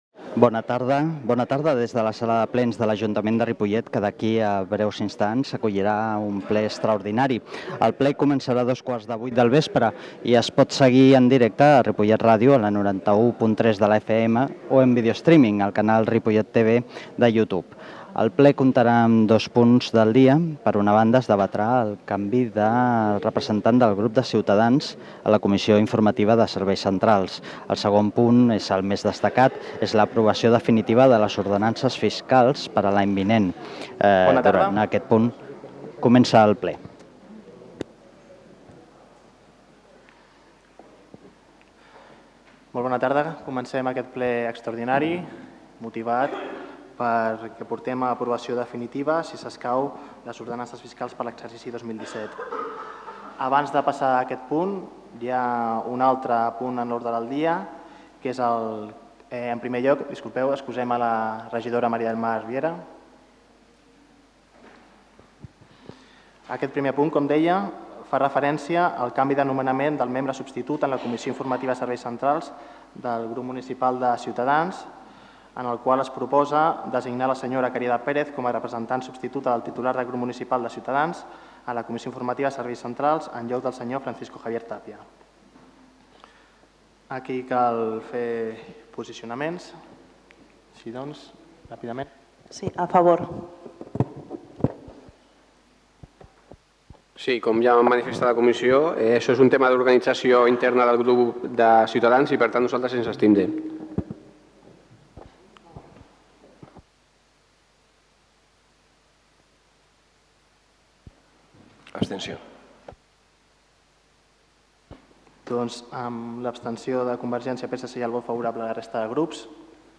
Acta sessió ple del 15 de desembre de 2016 (extraordinari ordenances)